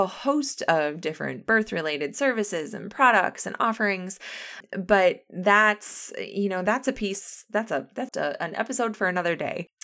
I think I got it down to your normal crisp delivery.
Set overall volume and get rid (as much as possible) of the Essing distortion.